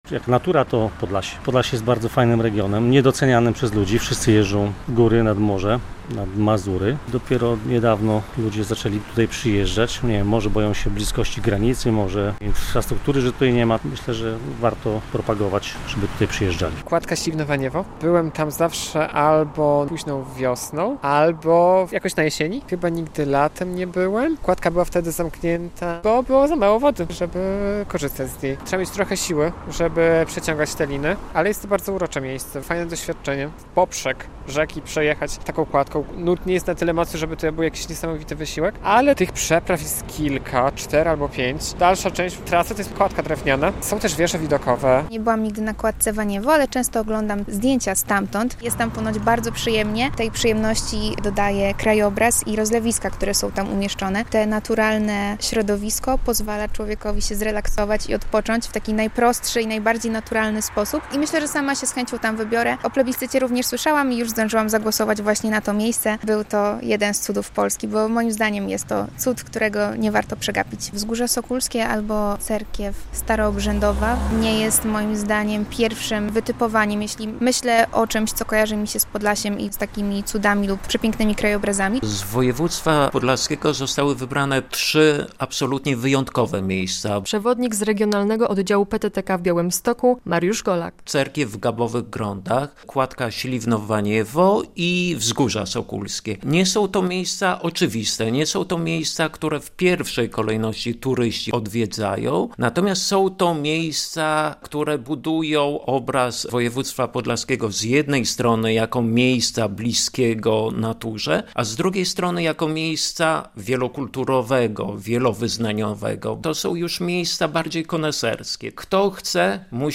Radio Białystok | Wiadomości | Wiadomości - Atrakcje Podlasia w finale plebiscytu "National Geographic".